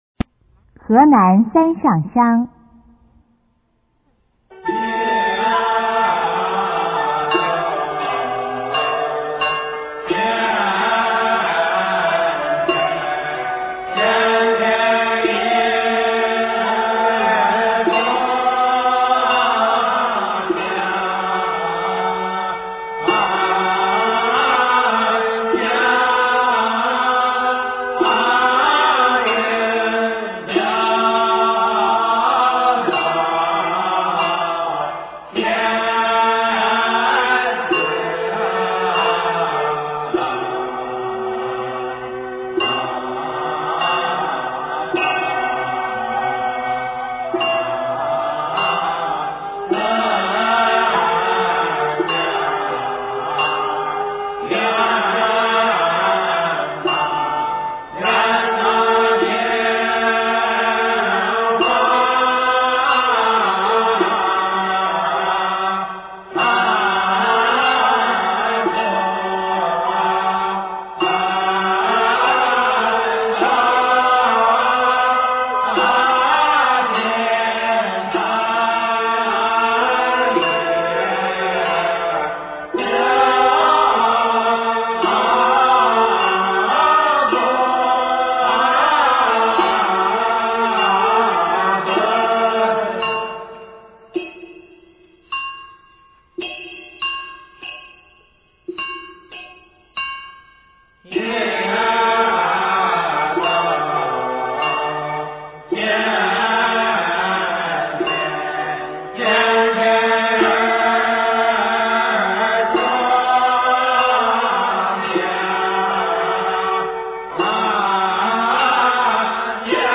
中国道教音乐 全真正韵 河南三上香